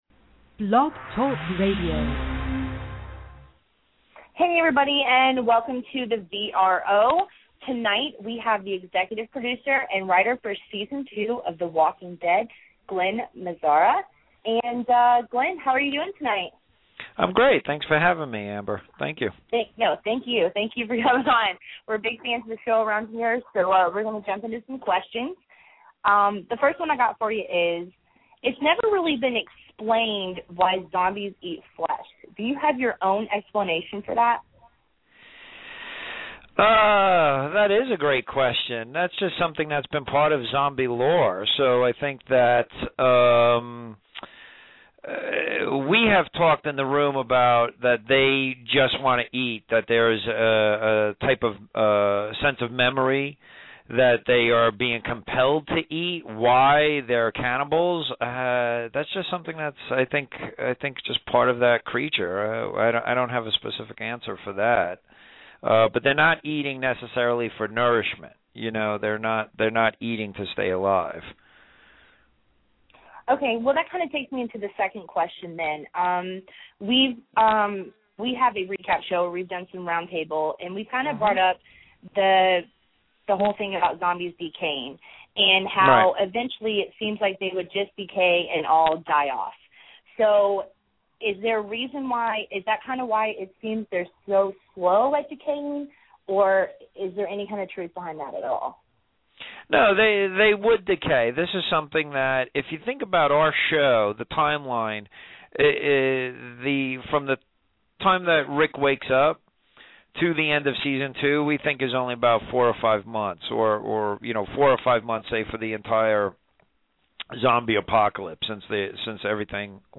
Glen Mazzara "The Walking Dead" Interview